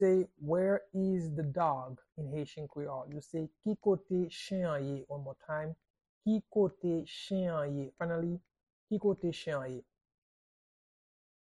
Pronunciation and Transcript:
How-to-say-Where-is-the-dog-in-Haitian-Creole-–-Ki-kote-chen-an-ye-pronunciation-by-a-Haitian-tutor.mp3